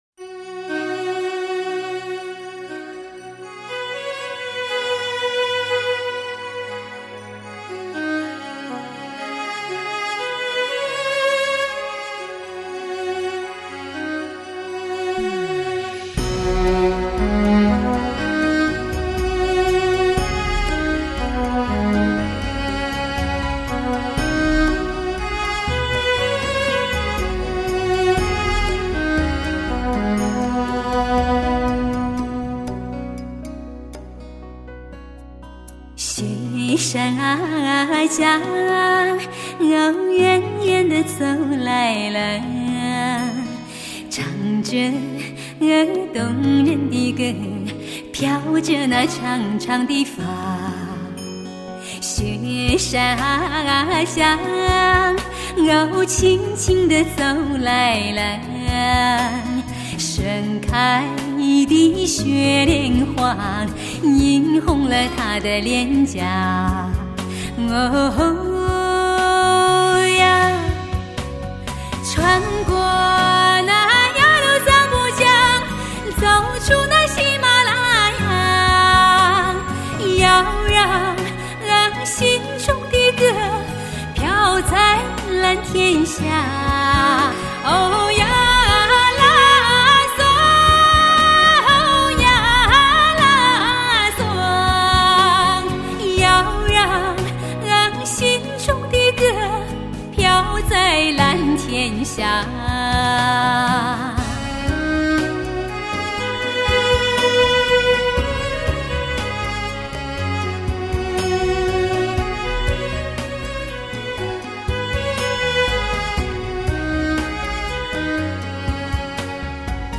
好嗓子，謝謝分享